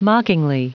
Prononciation du mot mockingly en anglais (fichier audio)
Prononciation du mot : mockingly